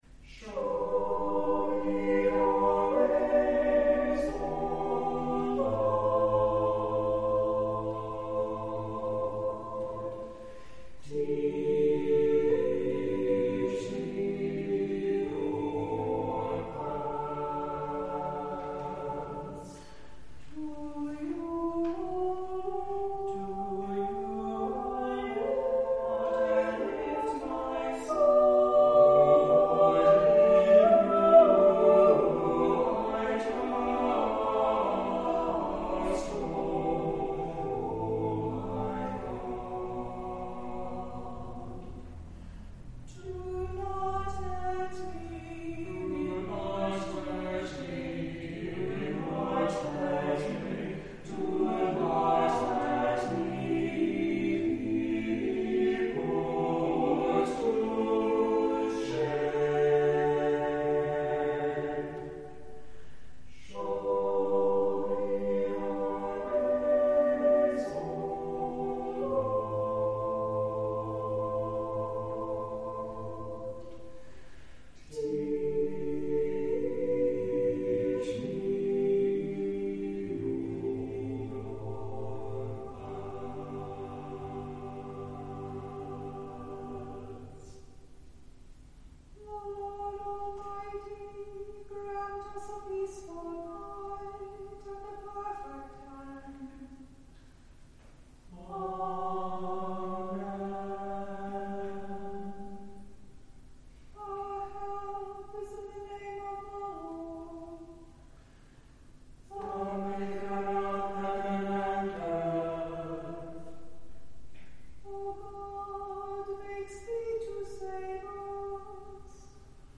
Worship and Sermons from Christ Episcopal Church in Little Rock, Arkansas
On Sundays, virtual and in-person services of prayers, scripture, and a sermon are at 8 a.m., 10:30 a.m., and 6 p.m., and a sung service of Compline begins at 7 p.m.
Christ Church Audio Home Categories Admin Compline 2025-02-23 The Compline Choir Download Size: 10 MB 1 Powered by Podcast Generator , an open source podcast publishing solution | Theme based on Bootstrap